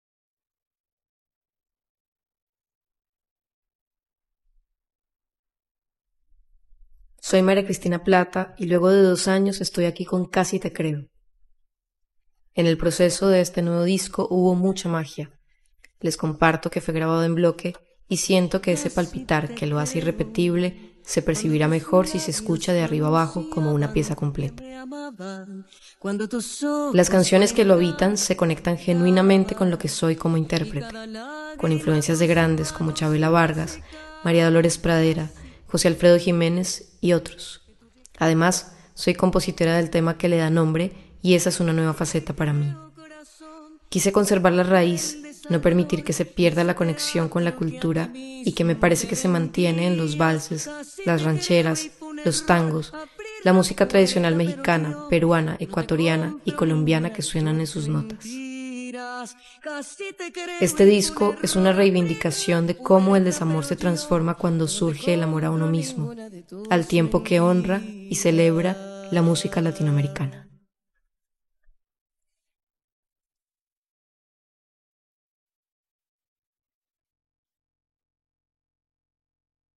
Maria Cristina Plata, Cantante